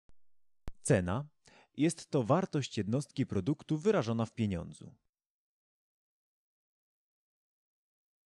lektor